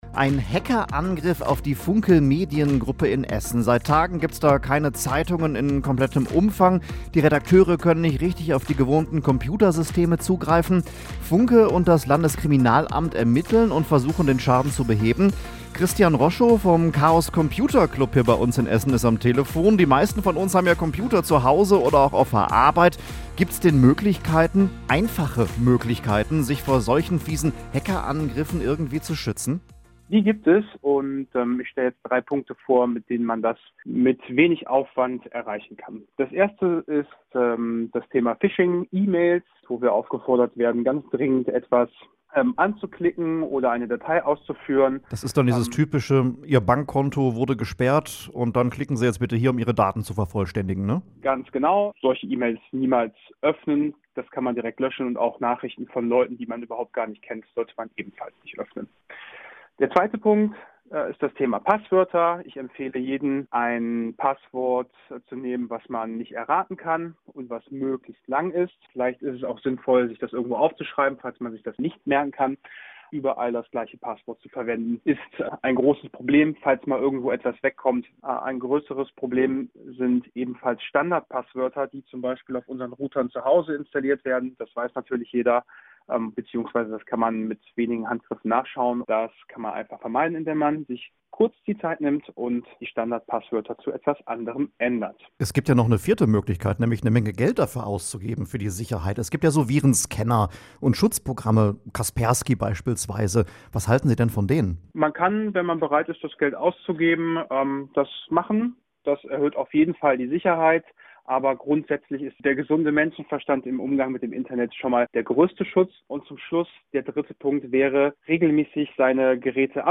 computer-club-mitschnitt.mp3